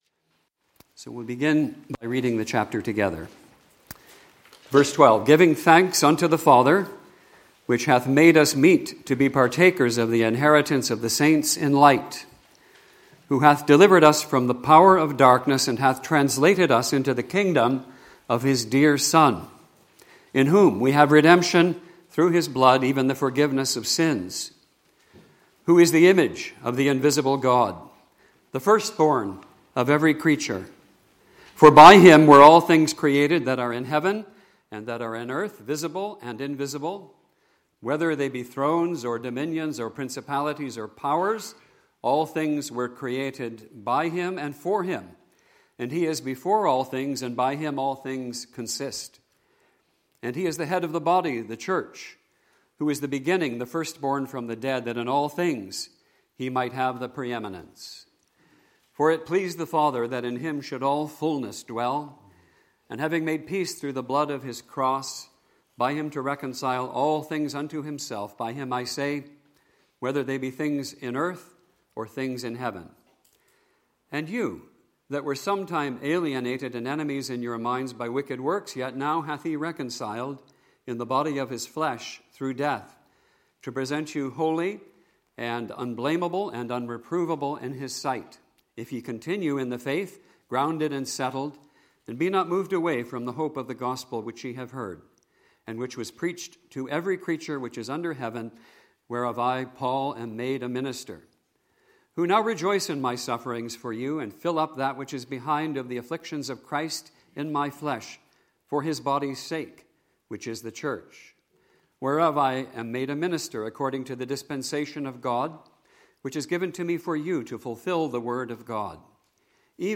Bible Reading in Colossians 1:15-28 (86 mins)
2025 Easter Conference